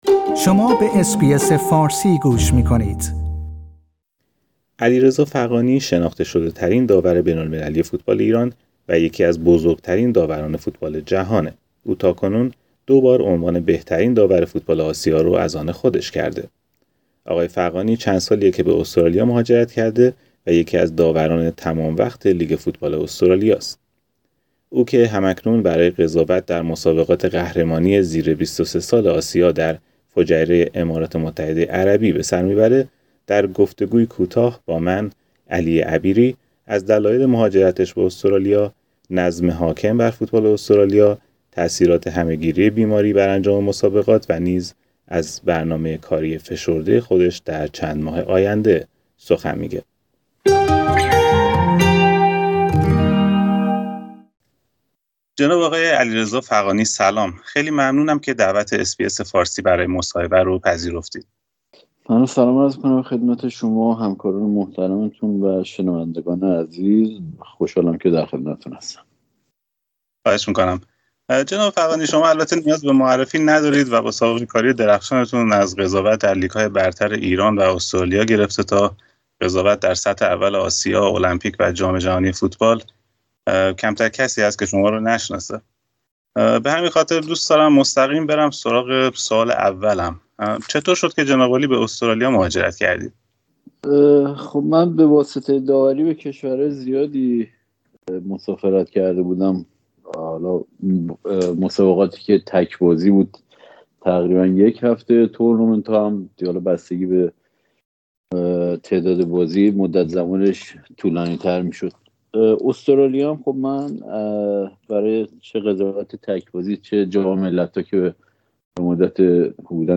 او که هم اکنون برای قضاوت در مسابقات قهرمانی زیر ۲۳ سال آسیا در فجیره امارات متحده عربی به سر می برد در گفتگویی کوتاه با رادیو اس بی اس فارسی از دلایل مهاجرتش به استرالیا، نظم حاکم بر فوتبال استرالیا، تاثیرات همه گیری بیماری بر انجام مسابقات و نیز از برنامه کاری فشرده خود در چند ماه آینده سخن می گوید.